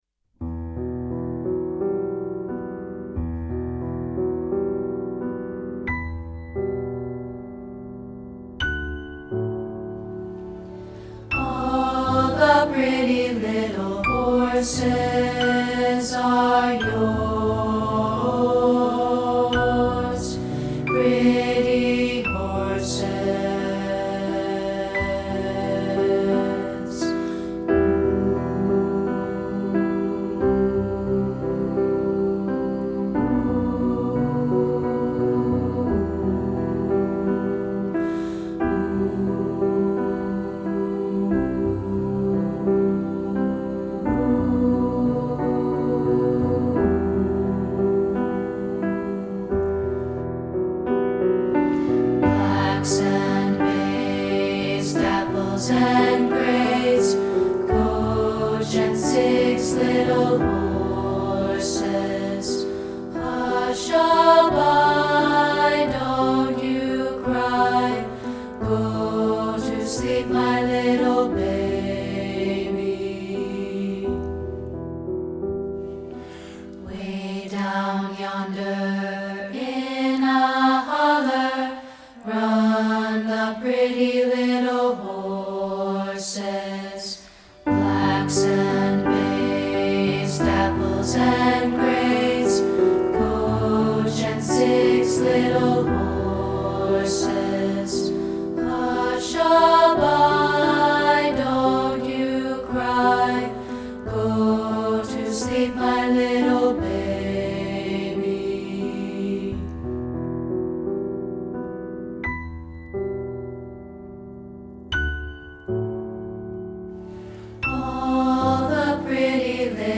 including this rehearsal track of part 3, isolated.